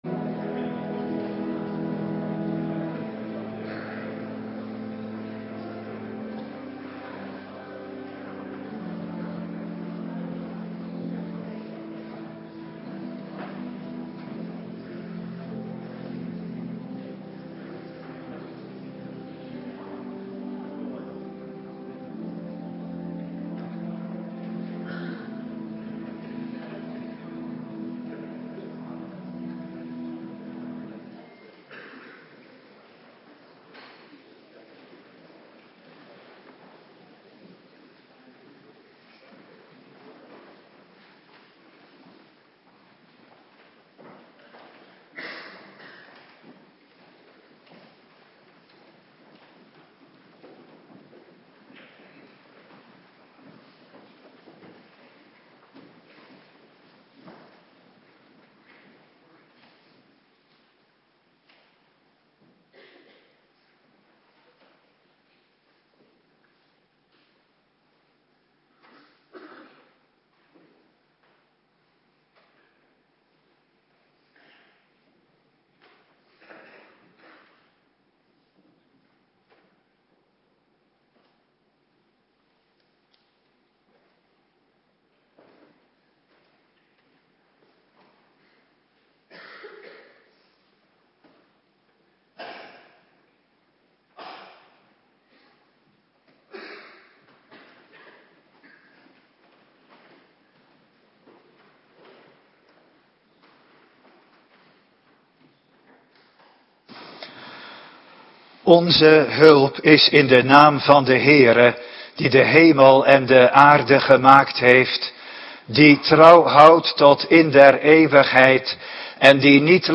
Avonddienst
Locatie: Hervormde Gemeente Waarder